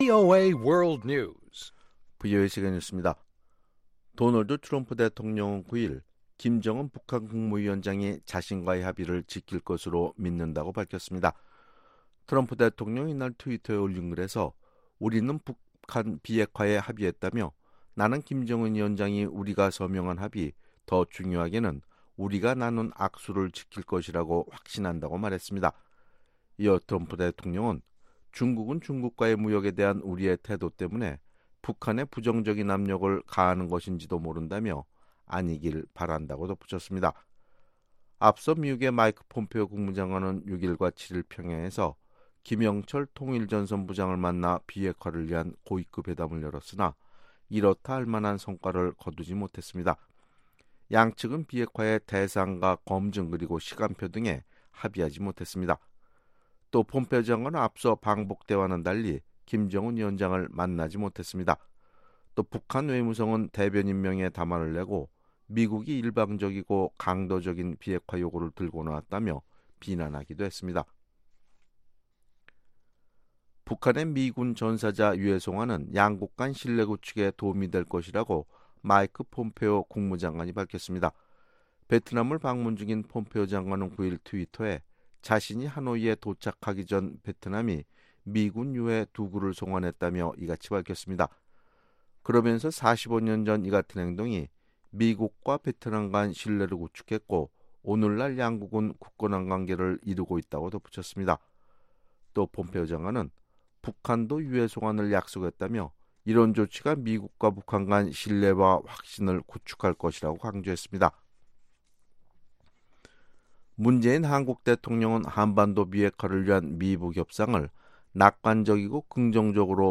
VOA 한국어 아침 뉴스 프로그램 '워싱턴 뉴스 광장' 2018년 7월 10일방송입니다. 마이크 폼페오 미국 국무장관이 평양에 도착해 비핵화 후속 협상을 시작했습니다. 국제원자력기구 IAEA는 북한 비핵화 검증에 중요한 역할을 할 준비가 돼 있으며 이런 준비 태세를 강화하고 있다고 밝혔습니다.